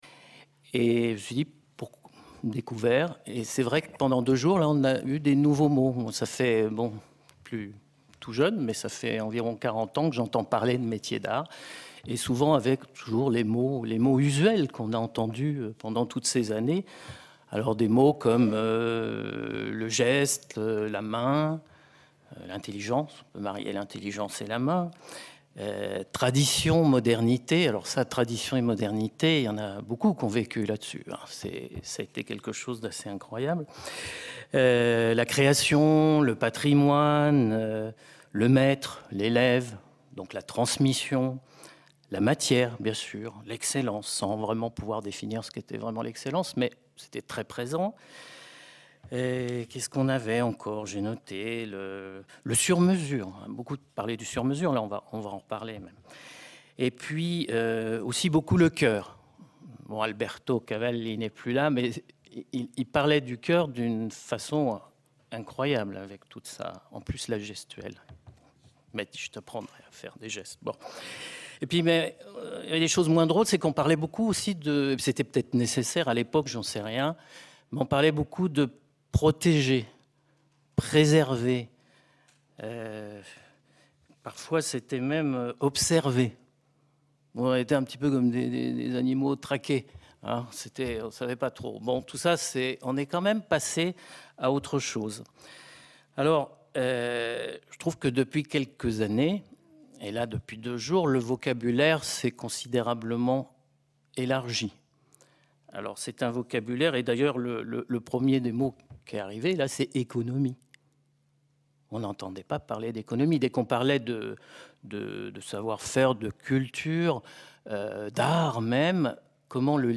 Extrait du discours de clôture